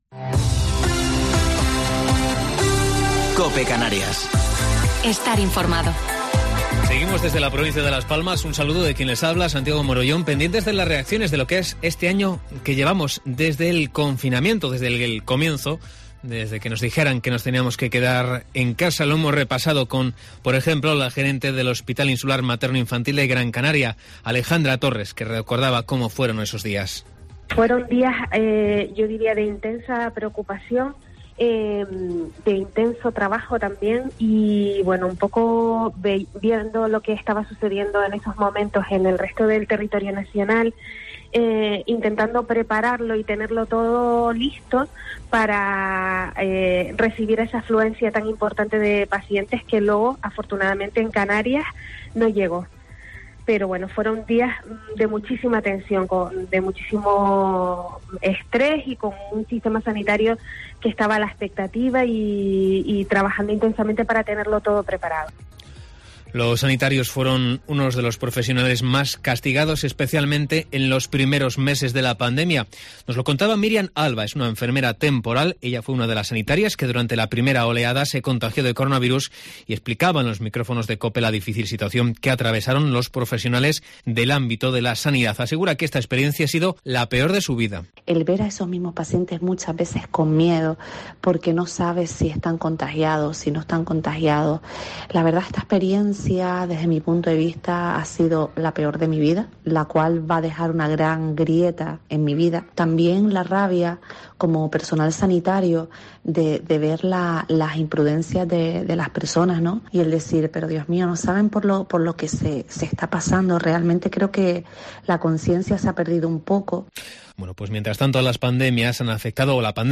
Informativo local 15 de Marzo del 2021